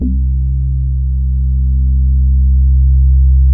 04BASS01  -L.wav